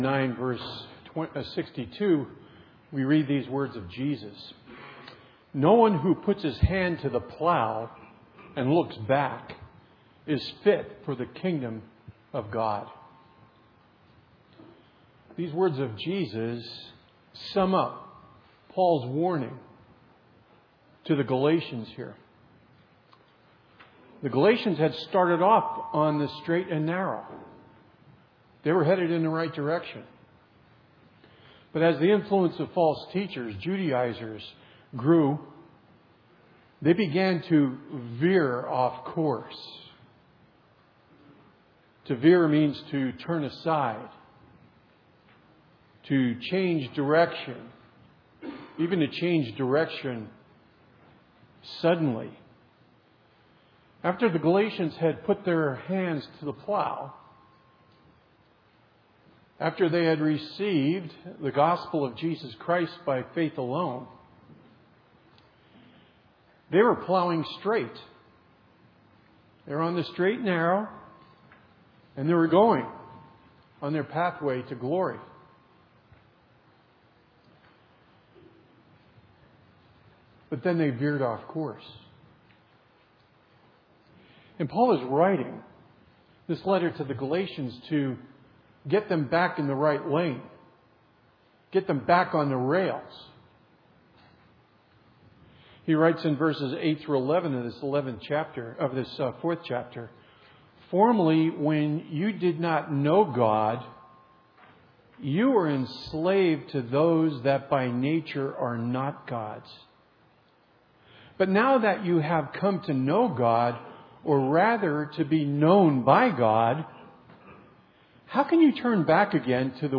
2017 Sermons